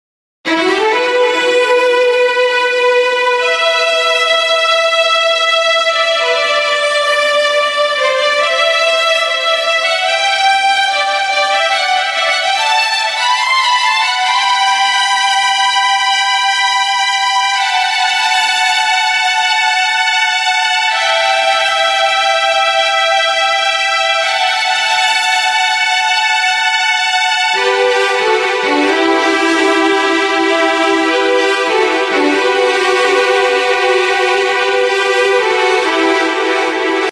HALion6 : Studio Strings